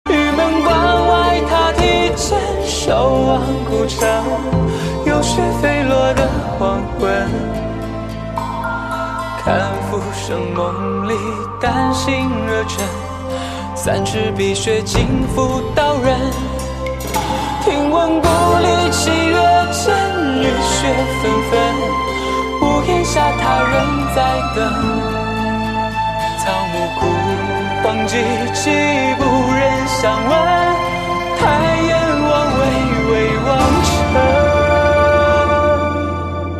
M4R铃声, MP3铃声, 华语歌曲 115 首发日期：2018-05-15 06:02 星期二